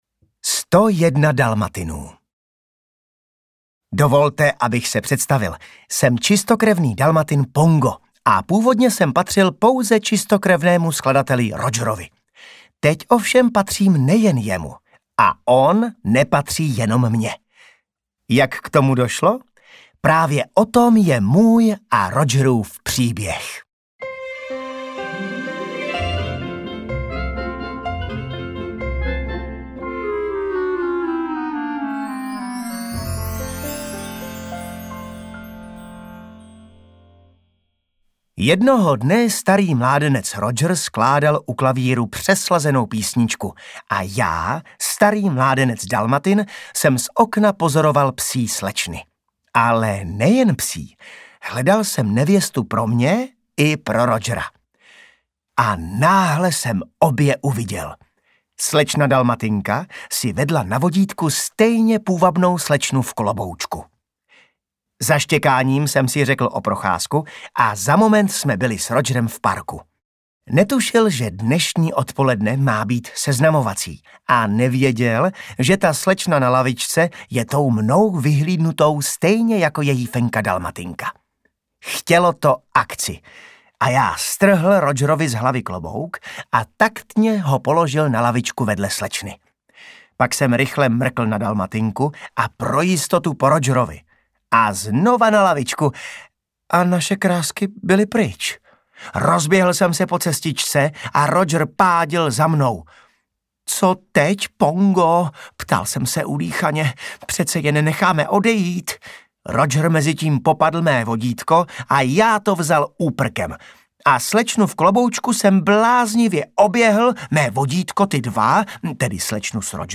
Audioknihy